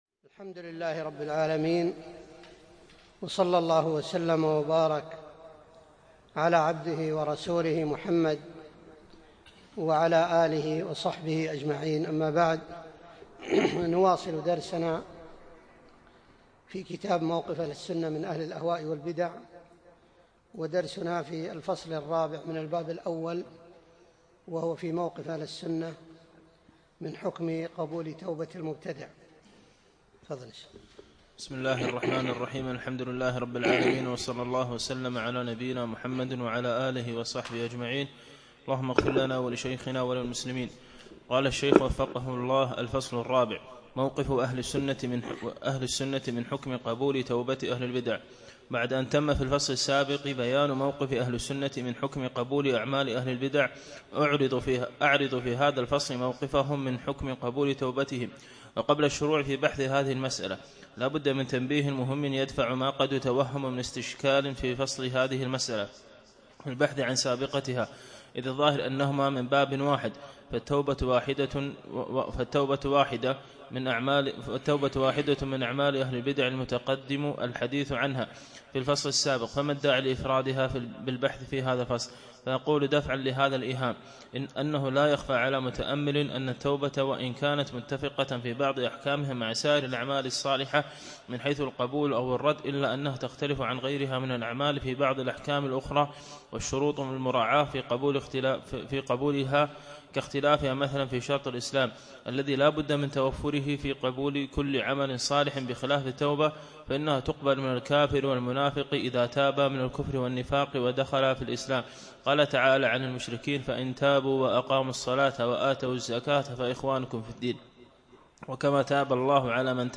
بعد العصر يوم الثلاثاء 21 جمادى الأول 1437هـ الموافق 1 3 2016م في مسجد كليب مضحي العارضية